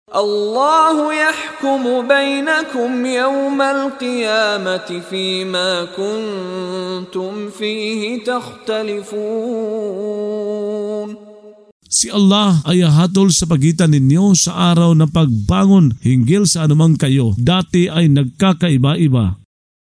Pagbabasa ng audio sa Filipino (Tagalog) ng mga kahulugan ng Surah Al-Haj ( Ang Pilgrimahe ) na hinati sa mga taludtod, na sinasabayan ng pagbigkas ng reciter na si Mishari bin Rashid Al-Afasy.